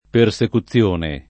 persecuzione [ per S eku ZZL1 ne ] s. f.